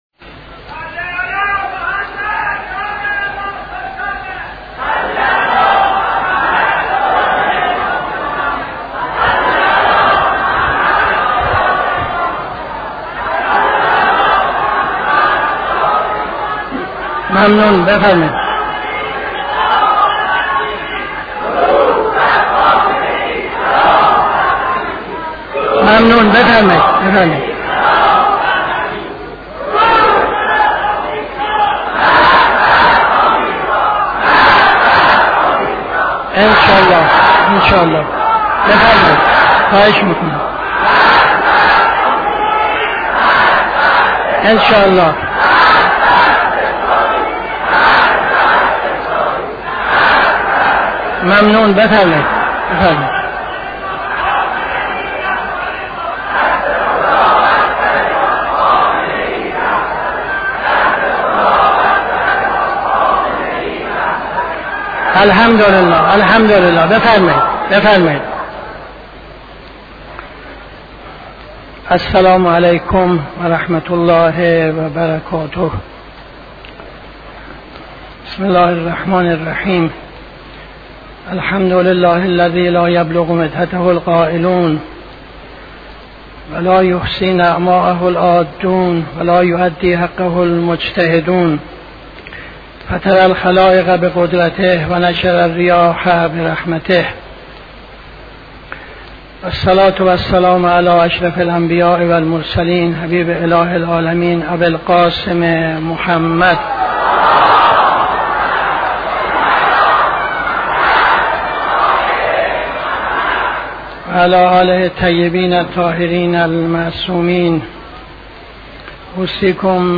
خطبه اول نماز جمعه 02-09-75